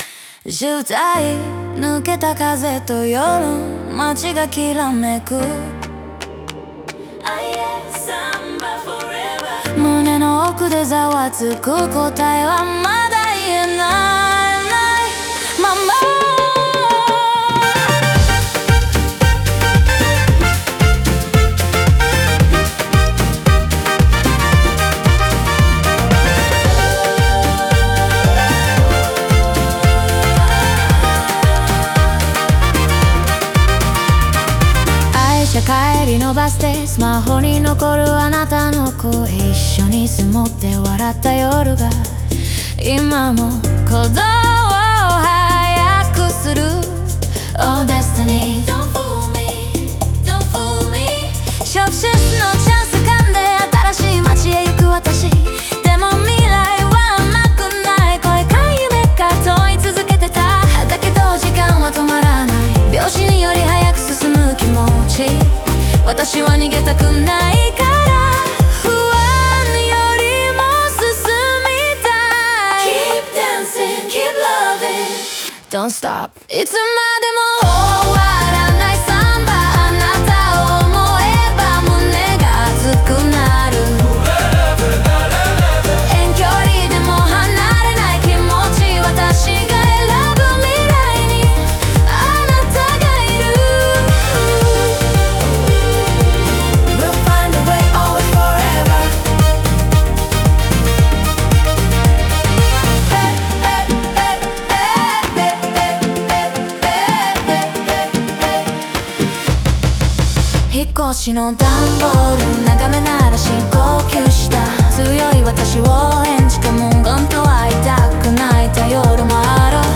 曲全体はサンバのリズムを軸にしながら、ダンス・ラテン・EDMテイストを融合させ、明るさと切なさが共存する構成です。